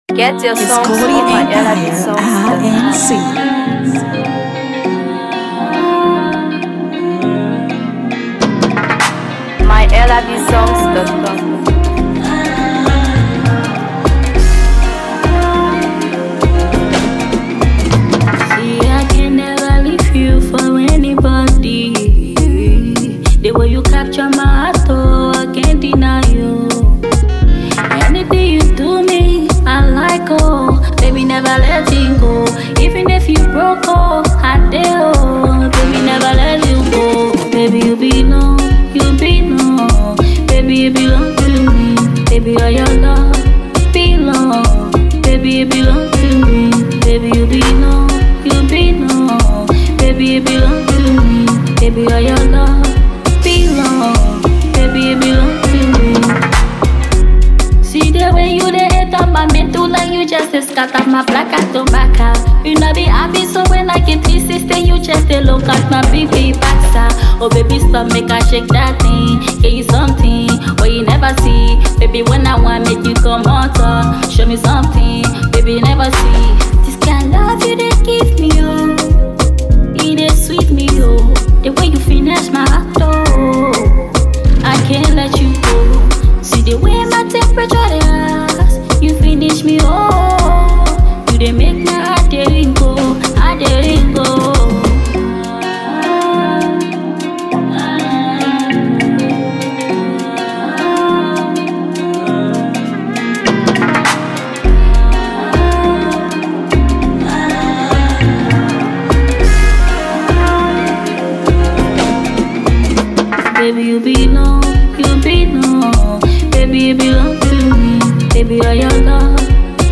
Afro PopMusic
enchanting melodies